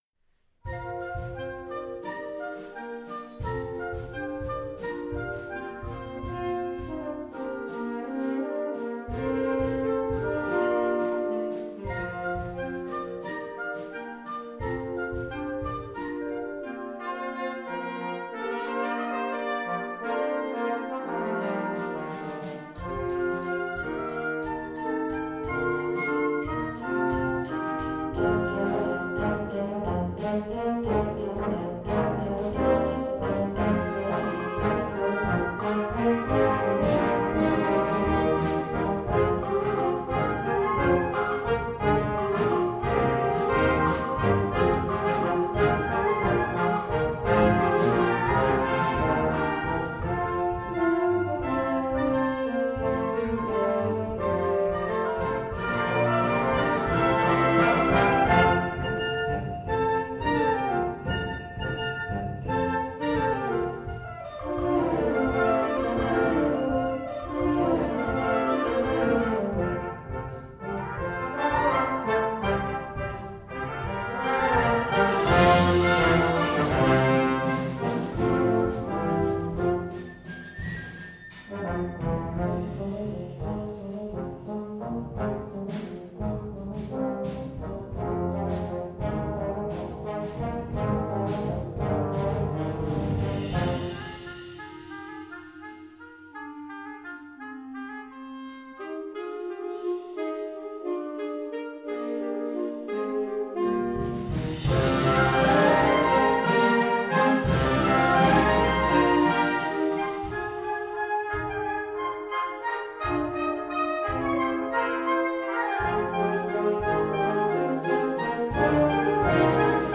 Weihnachtsmusik für Blasorchester
Besetzung: Blasorchester